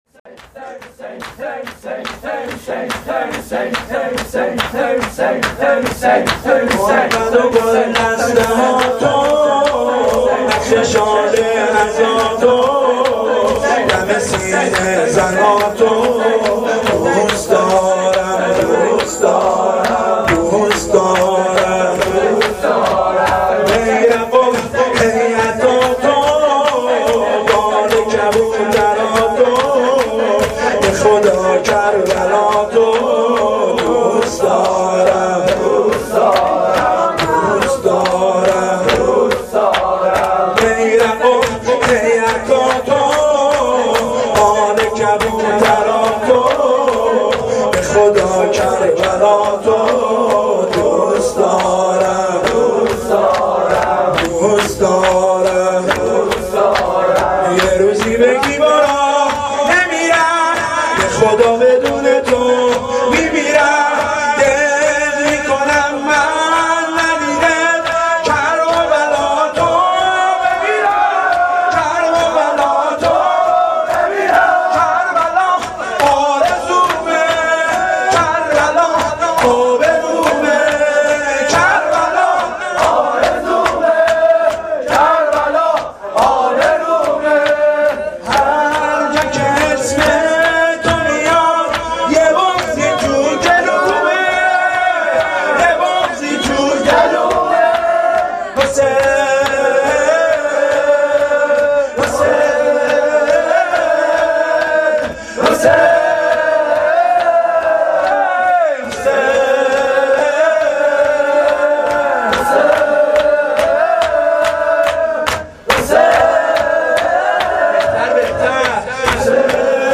حسینیه
شب دوم فاطمیه 1393, مداحی فاطمیه